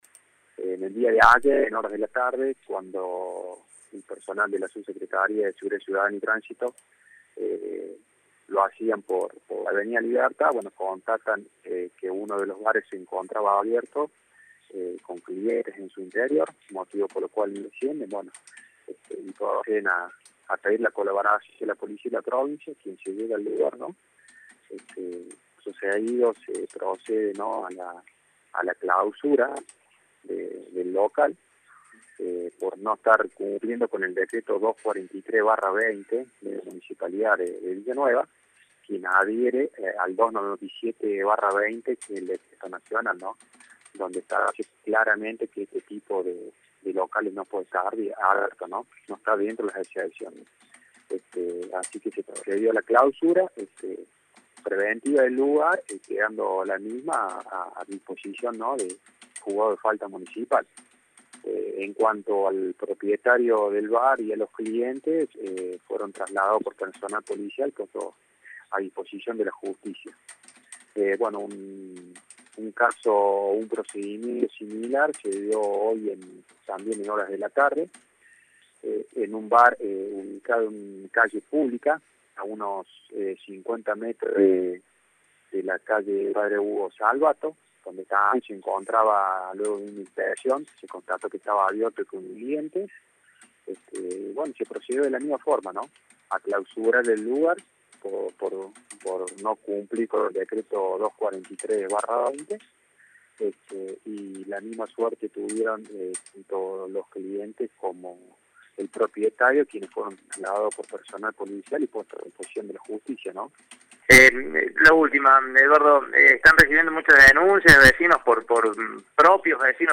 El responsable de Seguridad Ciudad, el ex comisario Eduardo Astesano habló con Radio Show sobre este tema.